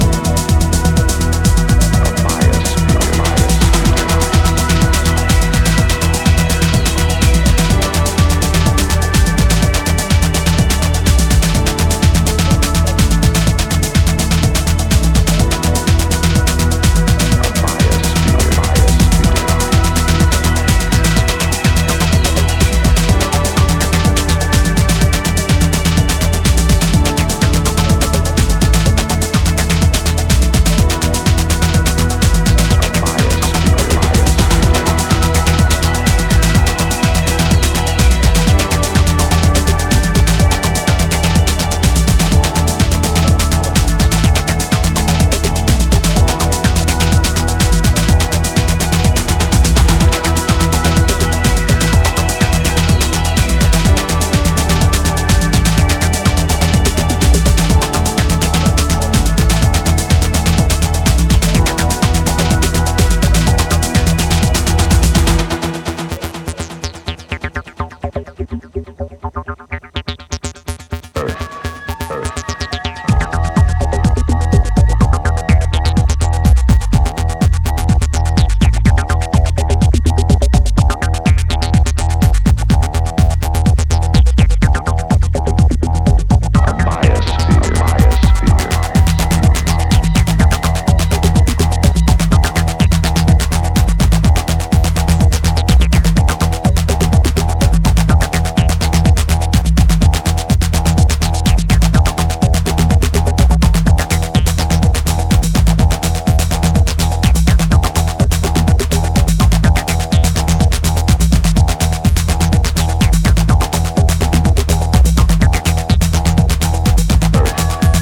126BPMで展開するスペーシーな陶酔と解放に息を飲むアンビエント・トランス